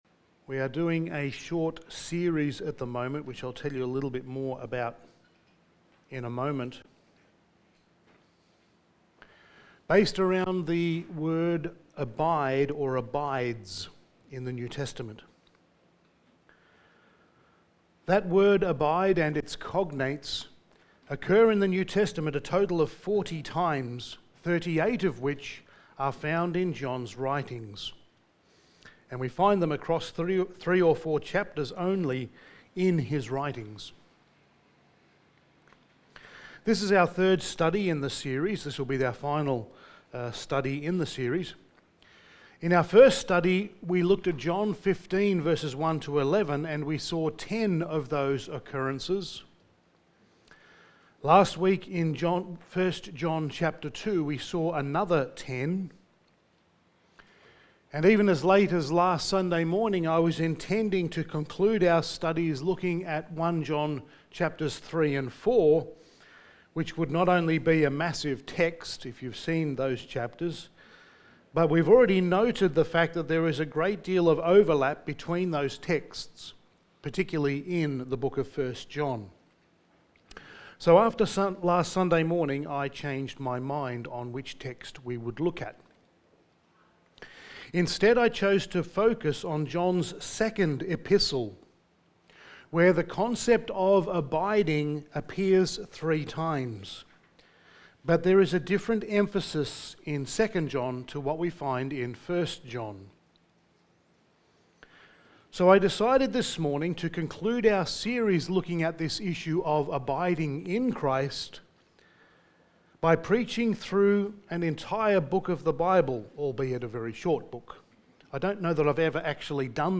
Passage: 2 John 1:1-13 Service Type: Sunday Morning